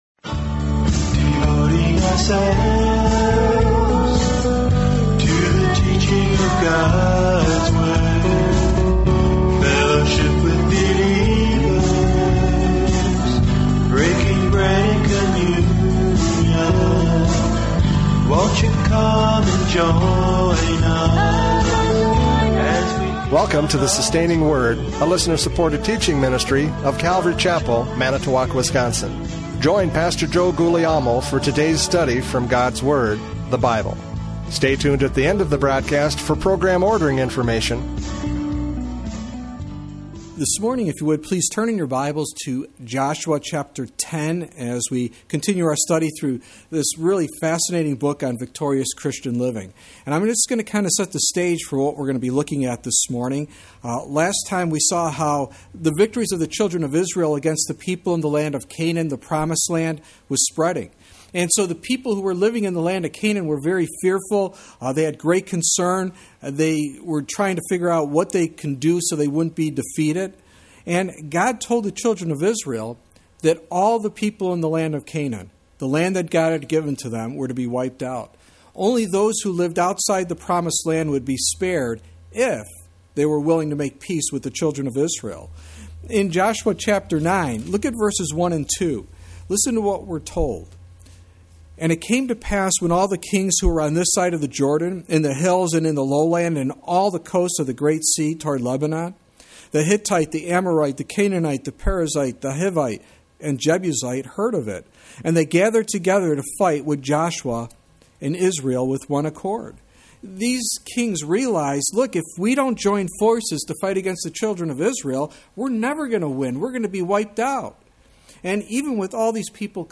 Joshua 10:1-14 Service Type: Radio Programs « Joshua 9:17-27 The Agony of Deceit!